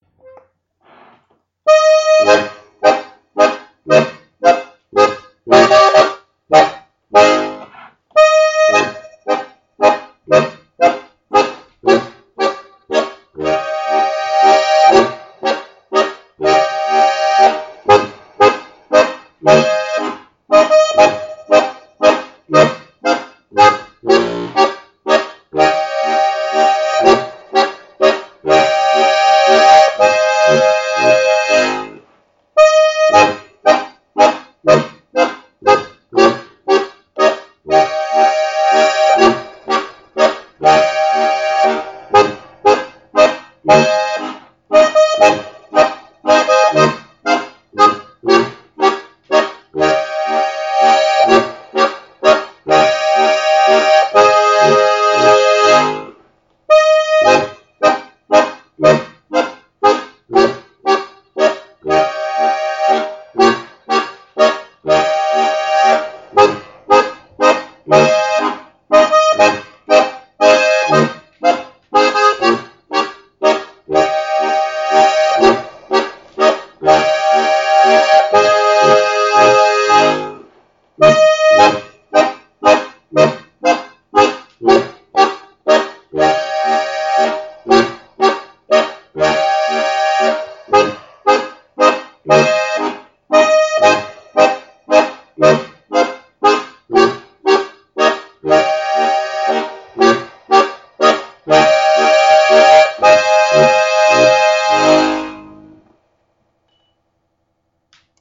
Hörbeispiele zum Mittanzen: